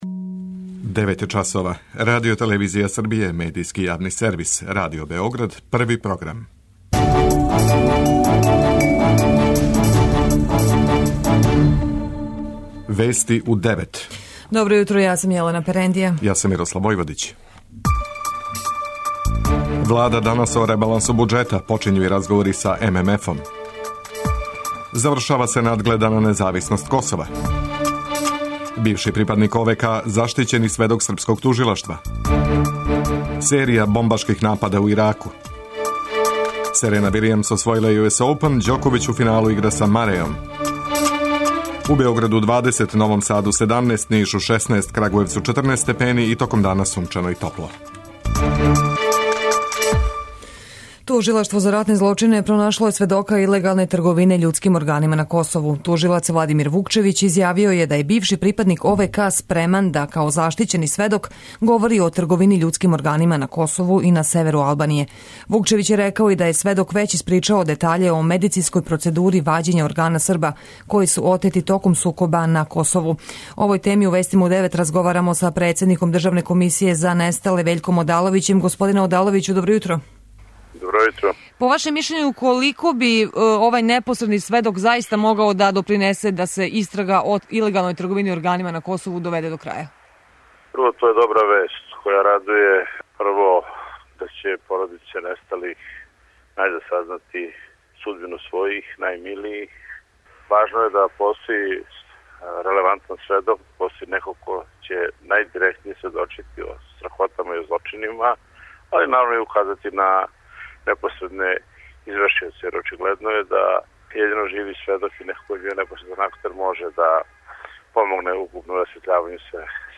преузми : 9.47 MB Вести у 9 Autor: разни аутори Преглед најважнијиx информација из земље из света.